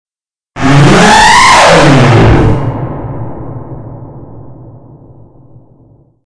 Space Elephant Téléchargement d'Effet Sonore
The Space Elephant sound button is a popular audio clip perfect for your soundboard, content creation, and entertainment.